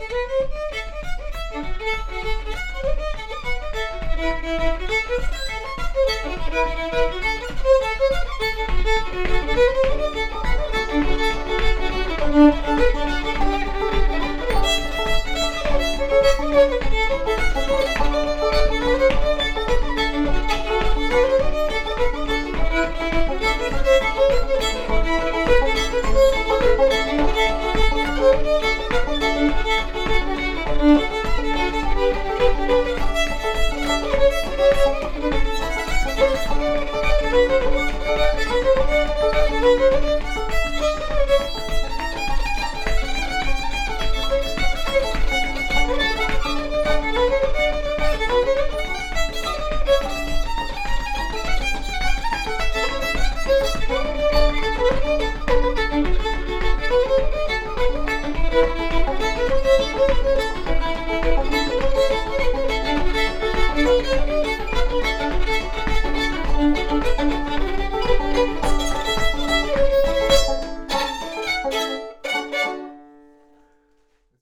Reel